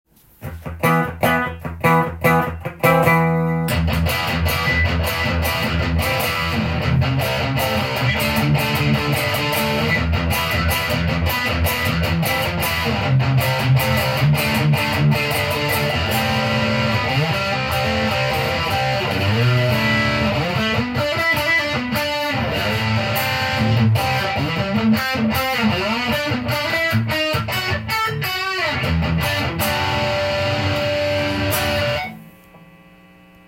かなり派手なルックスのディストーション　Friedman　BE-OD
超ハイゲインサウンドからオーバードライブサウンドまで出る優れものです。
国産ではありませんが音の艶を感じるので　高品質なブランドの職人技ですね